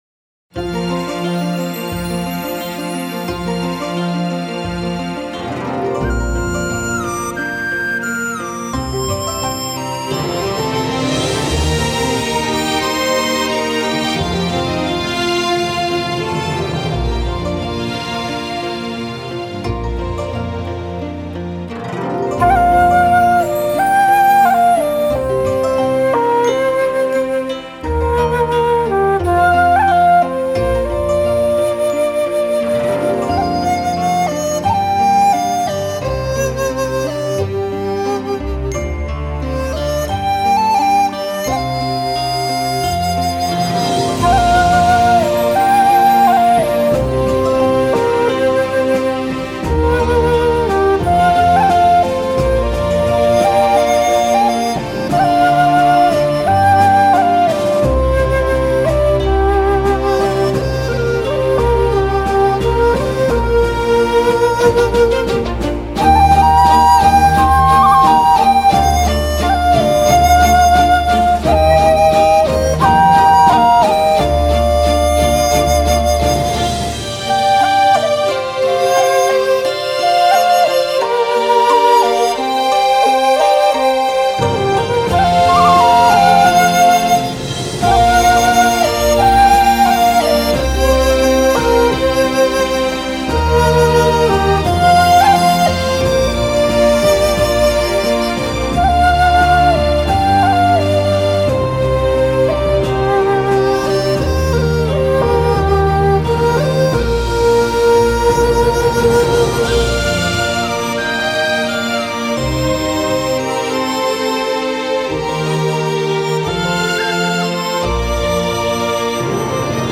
竹笛